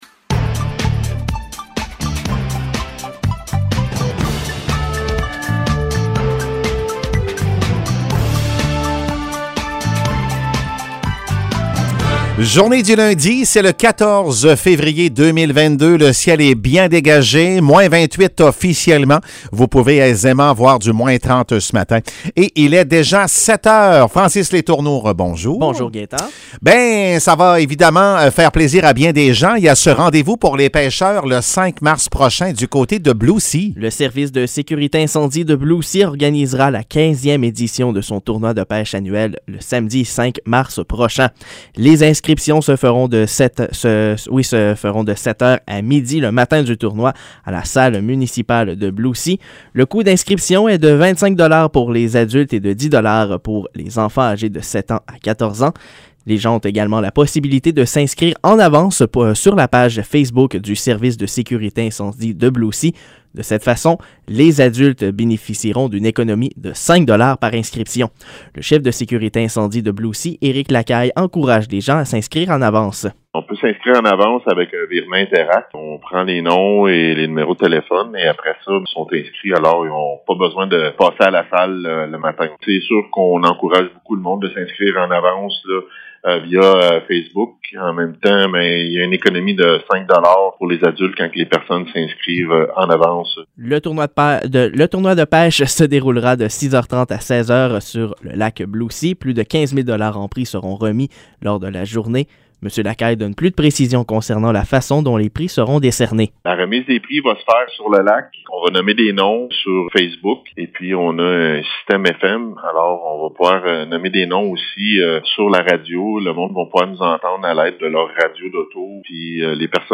Nouvelles locales - 14 février 2022 - 7 h